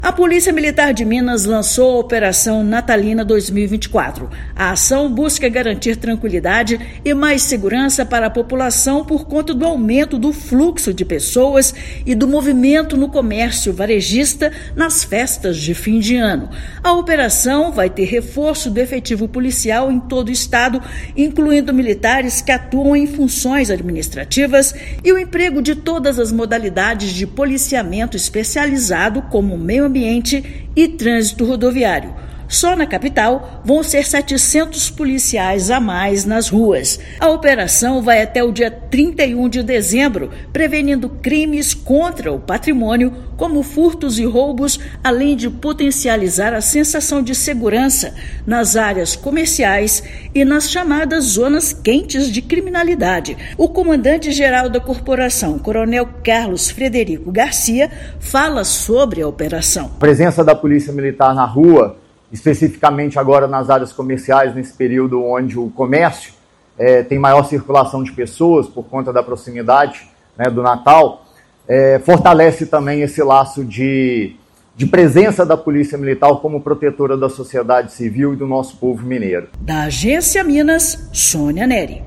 [RÁDIO] Polícia Militar de Minas Gerais lança operação para reforçar a segurança no Natal
Operação Natalina 2024 tem reforço no efetivo para a prevenção de crimes no fim de ano, especialmente no comércio, além dos golpes pela internet. Ouça matéria de rádio.